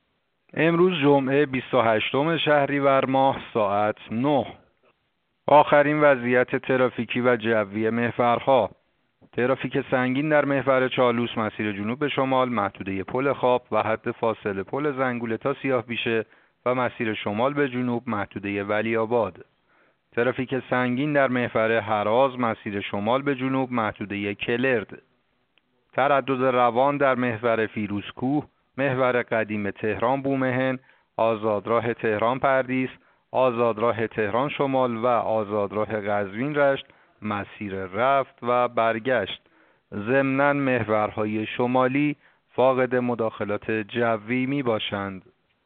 گزارش رادیو اینترنتی از آخرین وضعیت ترافیکی جاده‌ها ساعت ۹ بیست و هشتم شهریور؛